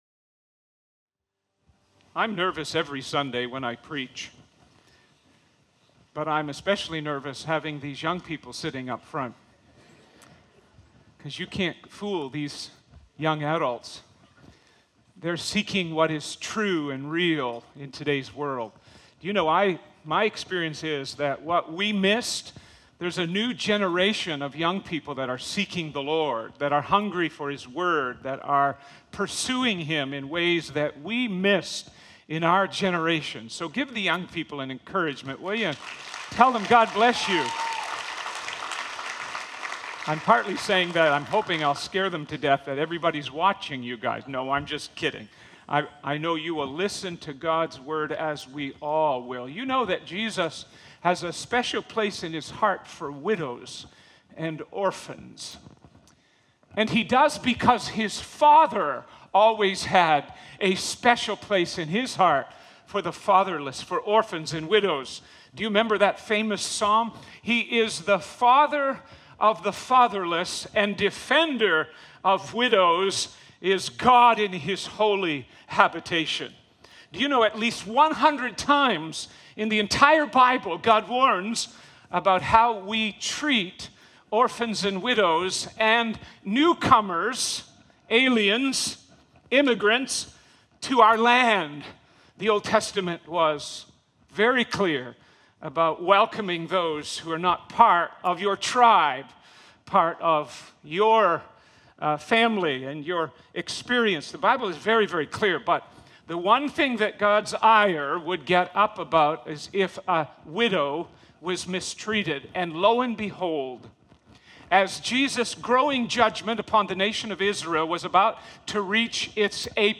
This passage calls us to examine not only what we give, but how we give, pointing us to a posture of wholehearted devotion and dependence on the Lord. Sermon Notes: 1.